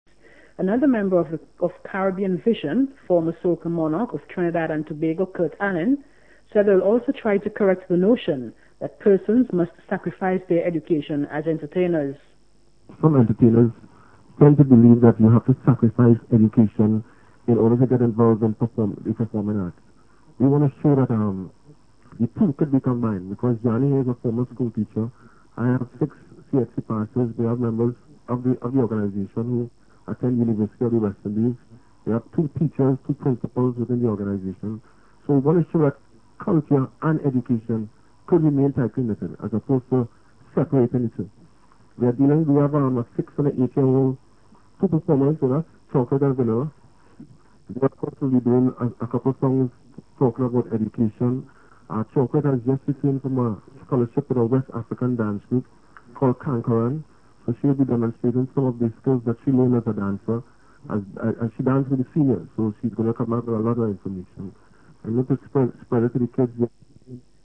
As with acrolectal English at other anglophone locations of the Caribbean one finds typical features such as the lack of syllable-final /r/, no vowel length distinctions and a shift of fricatives to stops in words like think and this (which have interdental fricatives in standard English).
Trinidad_Tobago_Acrolectal.wav